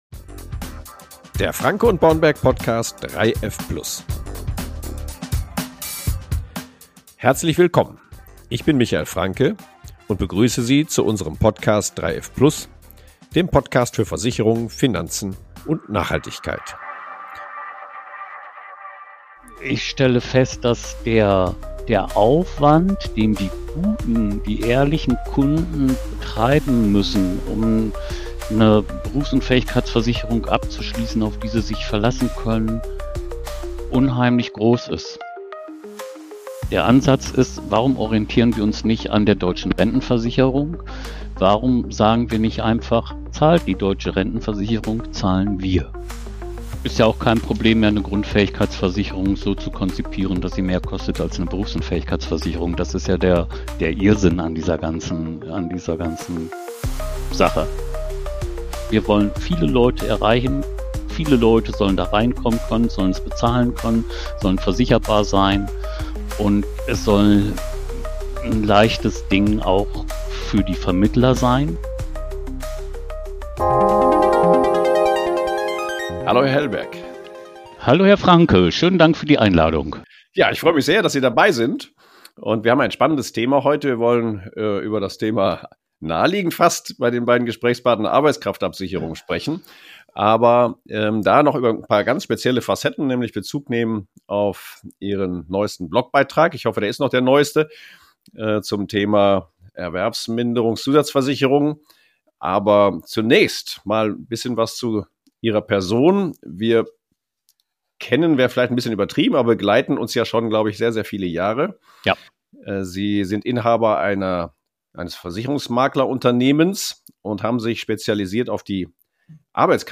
In diesem Gespräch